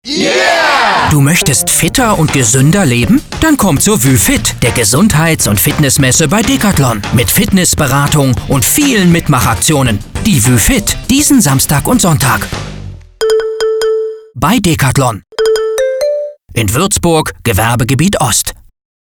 Hier ein Beispiel eines Radiospots unsers Fitness-Events WüFIT:
Radiospot-Decathlon-WÜFIT.mp3